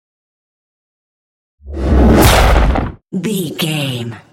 Whoosh to hit trailer
Sound Effects
Atonal
dark
futuristic
intense
tension